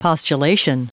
Prononciation du mot postulation en anglais (fichier audio)
Prononciation du mot : postulation